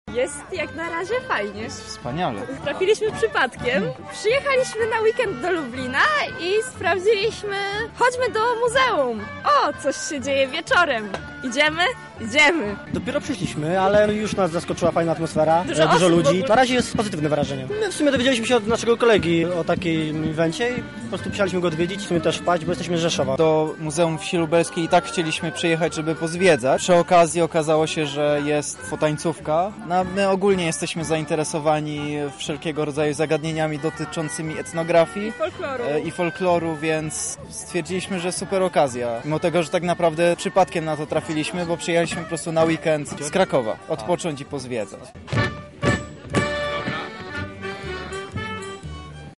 W minioną sobotę (14.07) w Muzeum Wsi Lubelskiej, jak co roku, odbyła się Potańcówka.
Jak się okazało, Potańcówka przyciągnęła nie tylko mieszkańców Lublina i okolic, ale także osoby z dalszych zakątków Polski: